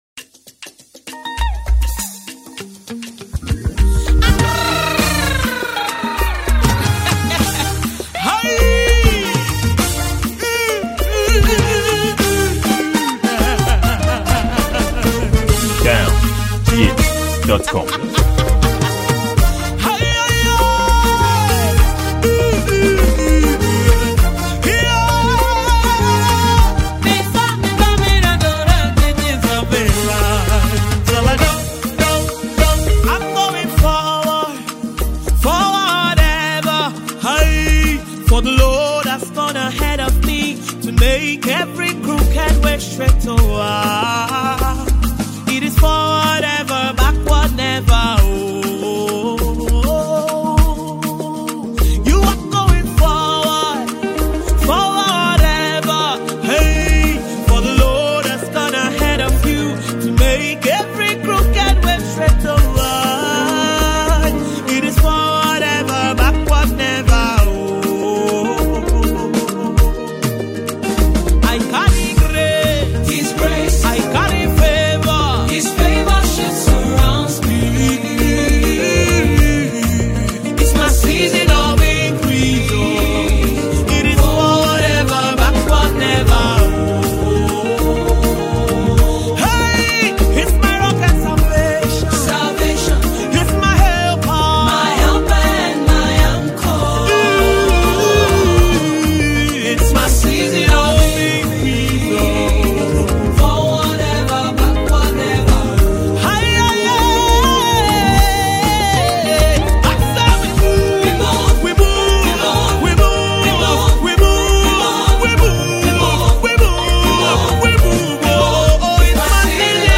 Gospel
Popular and renowned Nigerian gospel singer